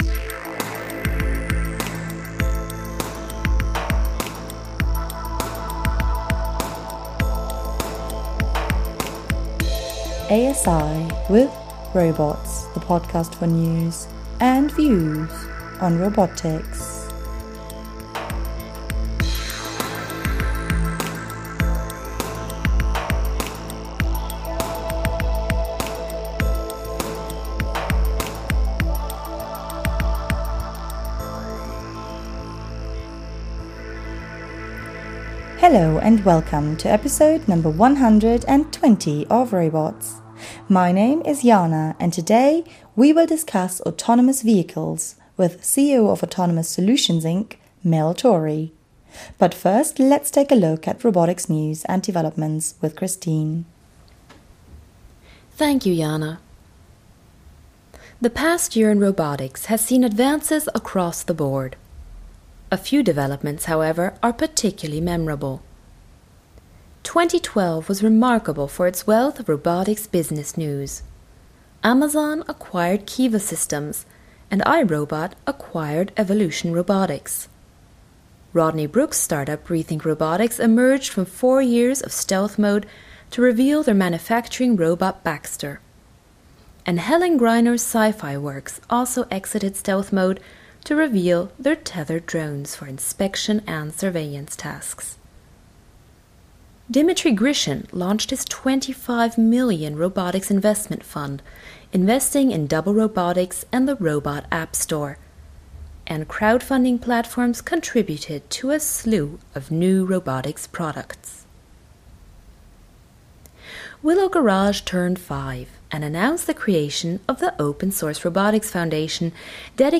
Links: Download mp3 (16.3MB) Subscribe to Robots using iTunes Subscribe to Robots using RSS Autonomous Solutions, Inc. website tags: autonomous vehicles , podcast Podcast team The ROBOTS Podcast brings you the latest news and views in robotics through its bi-weekly interviews with leaders in the field.